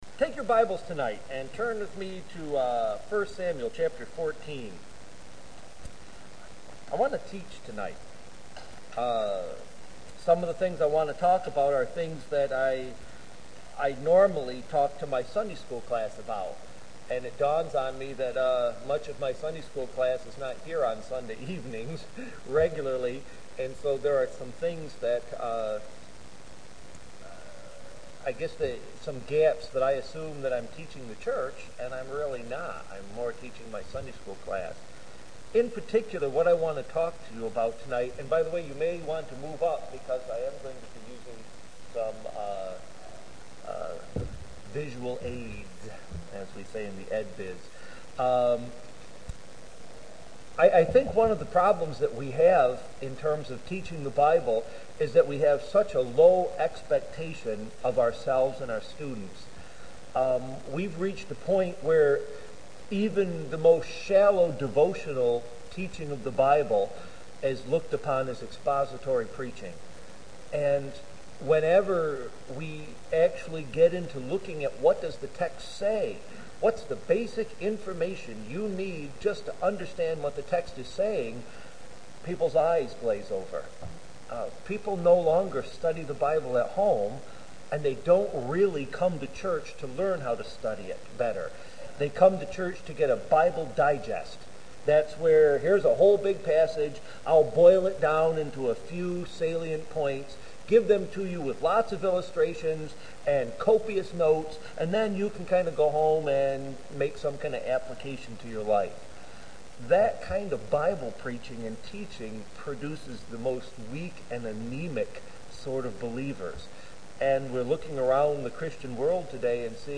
Unclassified Church: Brooktondale PM %todo_render% « How Does a Baptist Make Decisions Church Seeking Counsel